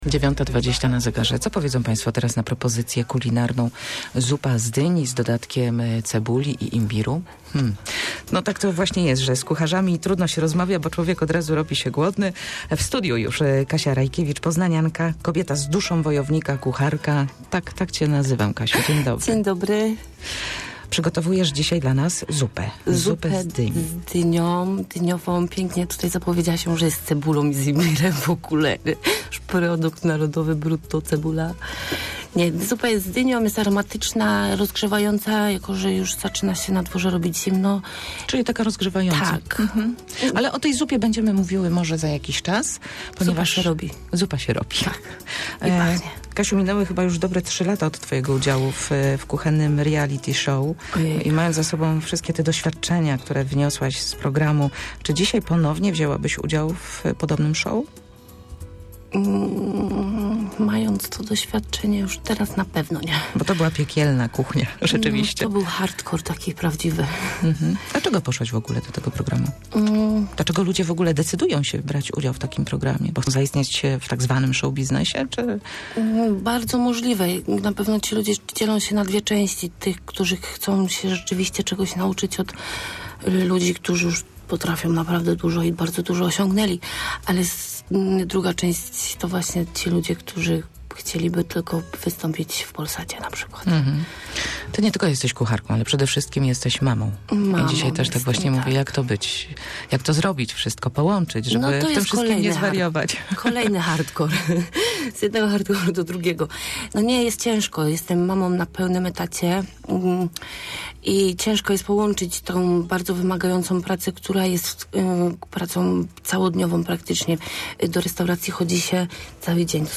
Ale dzisiaj nasz gość przyszedł na rozmowę z przepisem na rozgrzewającą zupę!